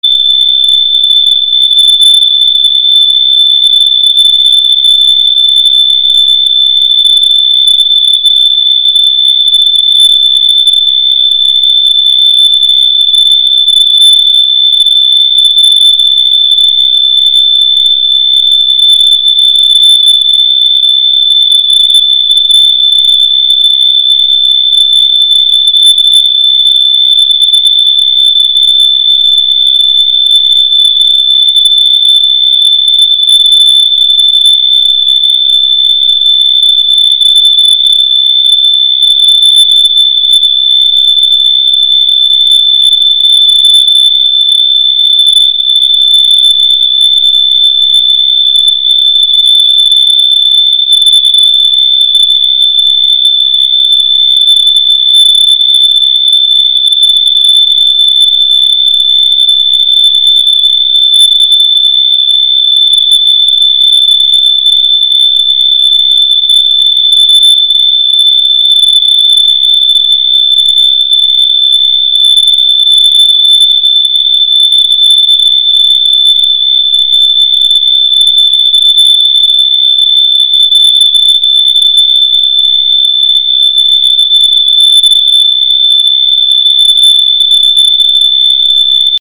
• Category: High frequency sounds
On this page you can listen to high frequency sounds.